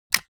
"Click Sound" From Mixkit